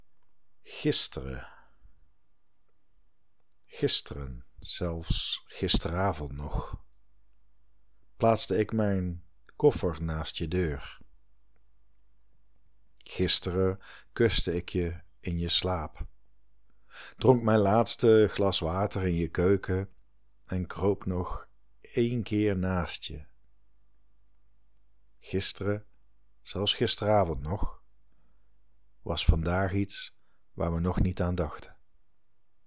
eigen productie, Tilburg